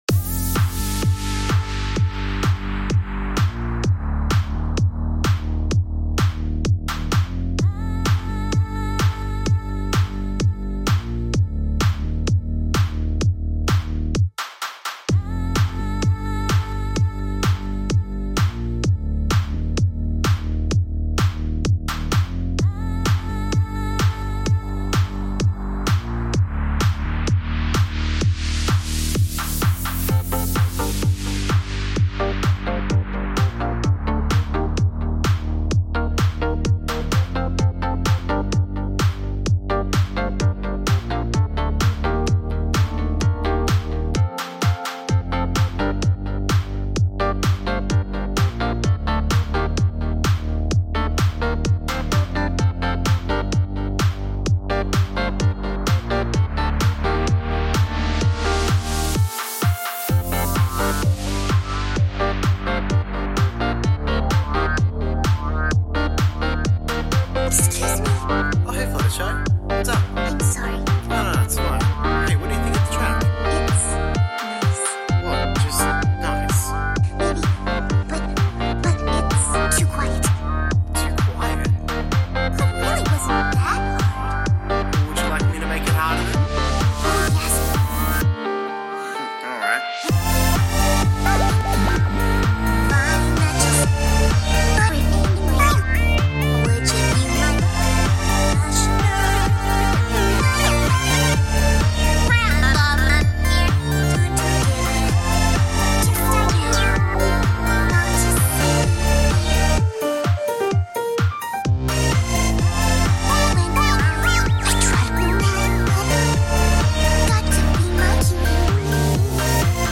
you made that really high arp (4:14-4:45) for the track.
...yes, I do know how cheesy the track's dialogue is.